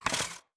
Skates and Ice Scrape.wav